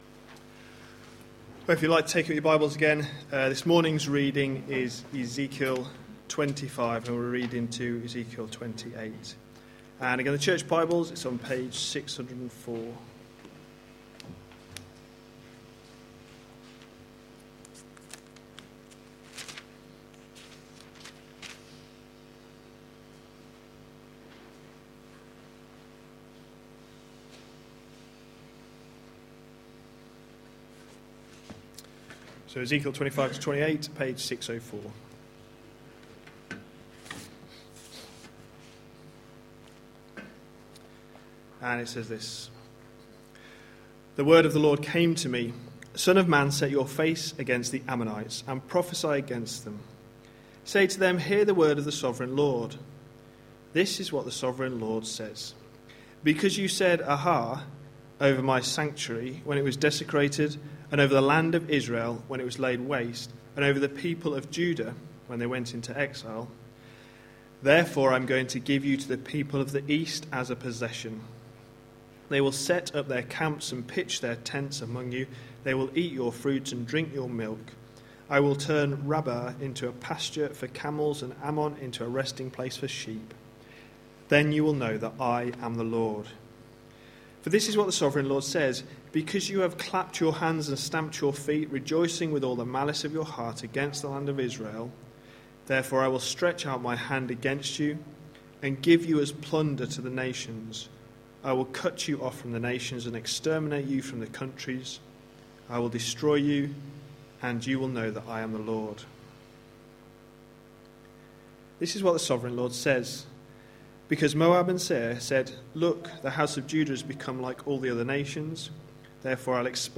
A sermon preached on 20th July, 2014, as part of our Ezekiel series.